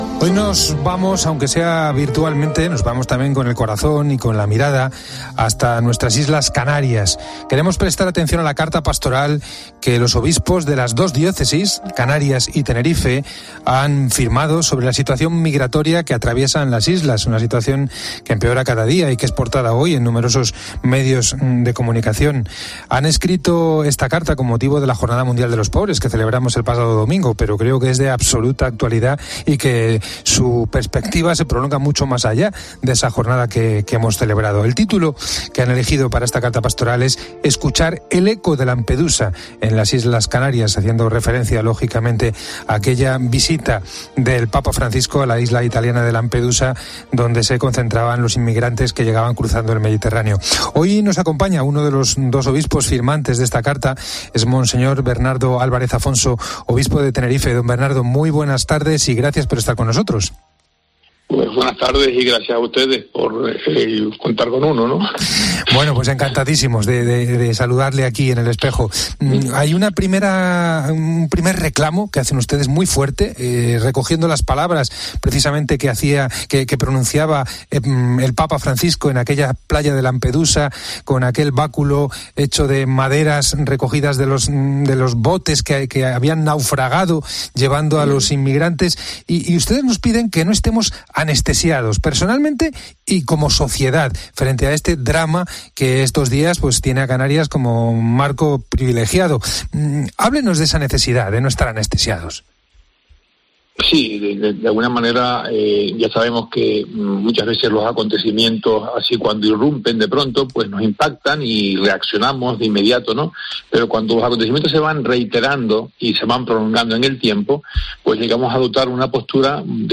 En una entrevista